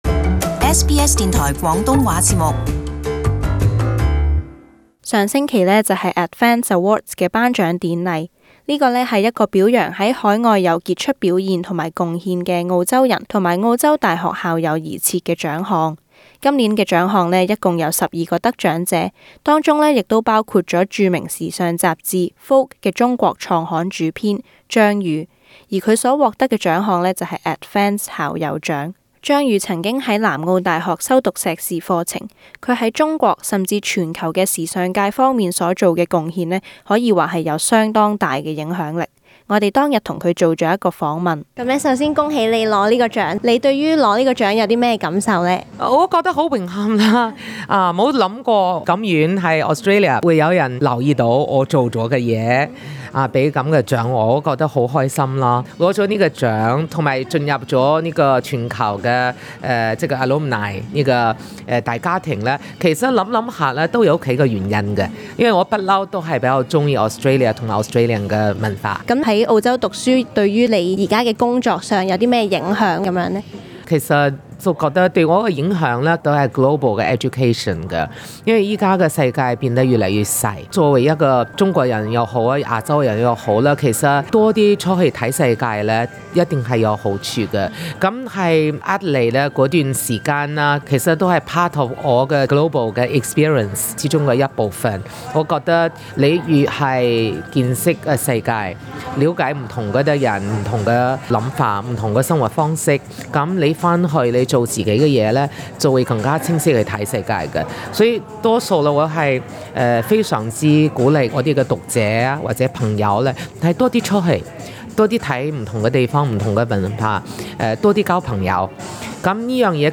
【社區專訪】Vogue雜誌中國創刊主編 - 張宇